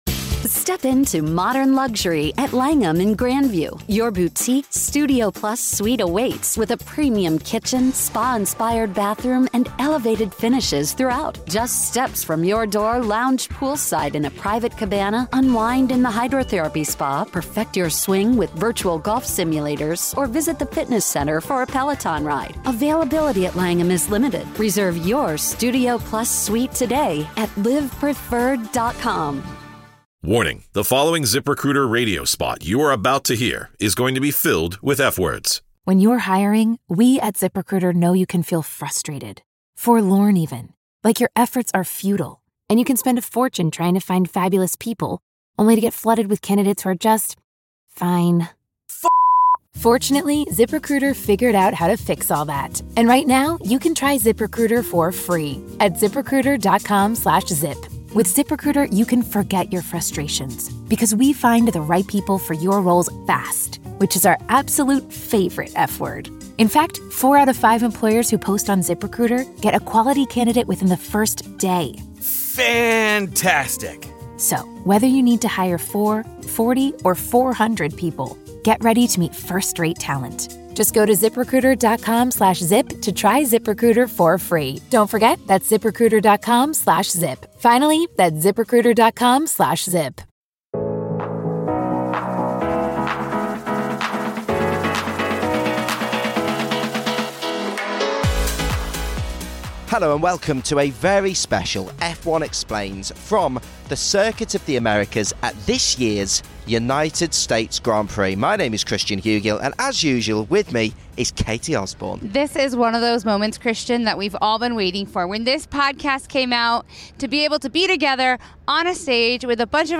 A Formula 1 legend, F1 fans with questions and a loud Texas crowd. F1 Explains took to the stage at the Circuit of The Americas for our first ever live show!